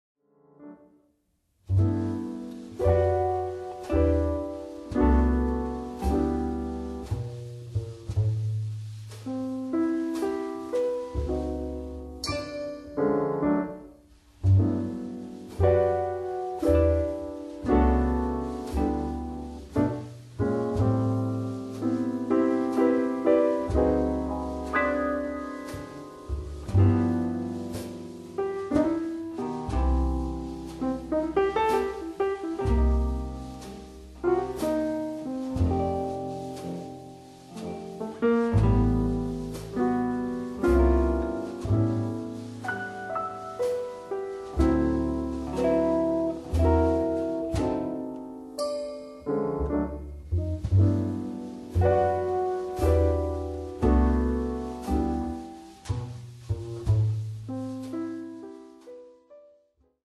Category: Broadway, Film and Shows